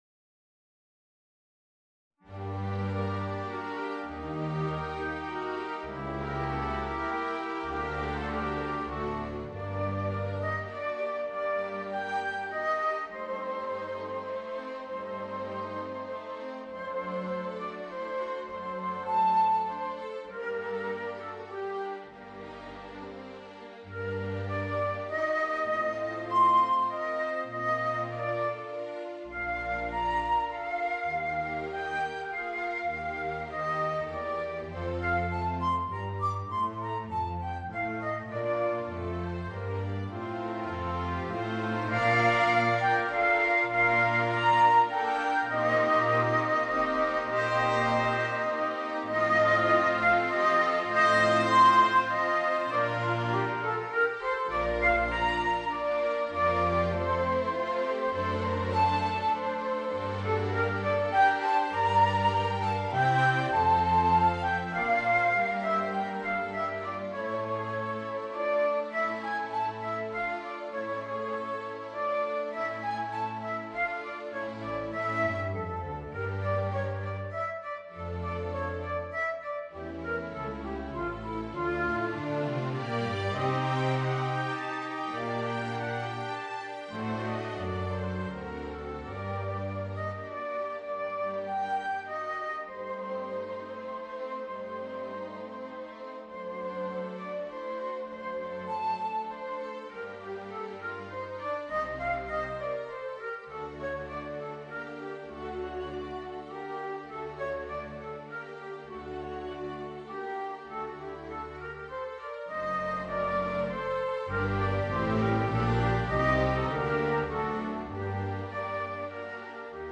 Voicing: Tenor Saxophone and Orchestra